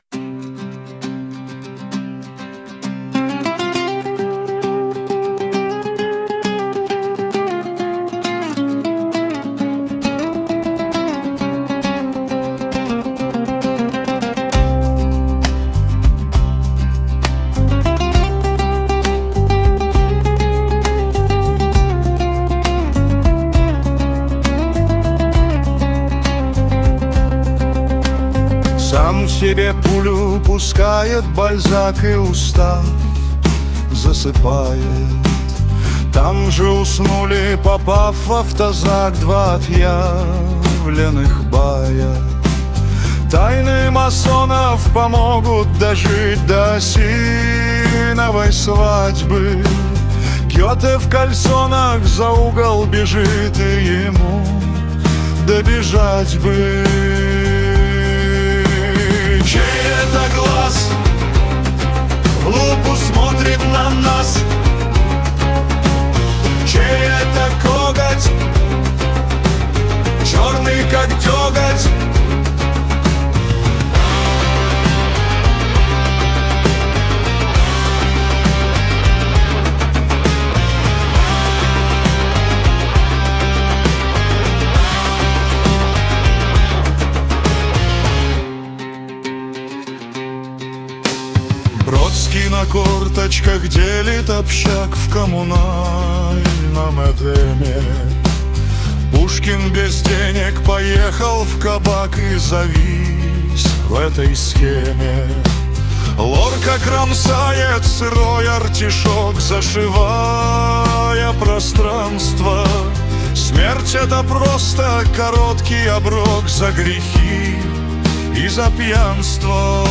• Жанр: Комедия